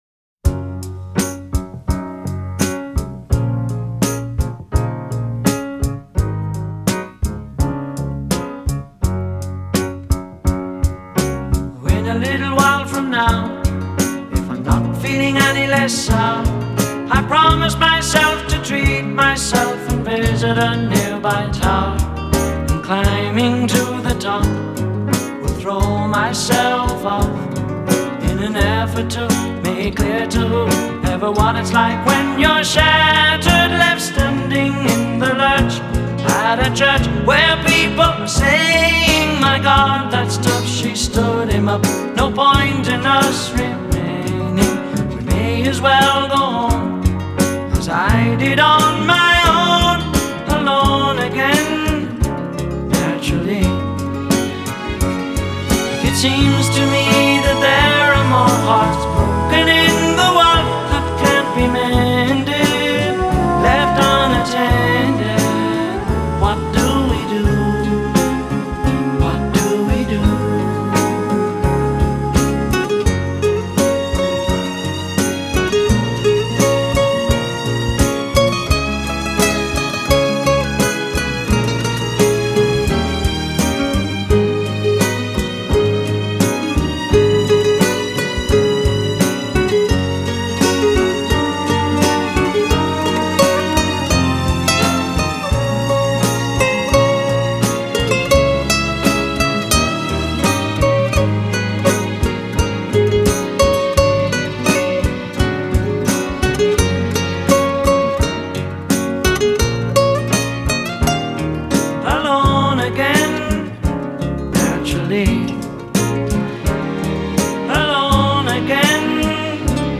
BPM85
Audio QualityMusic Cut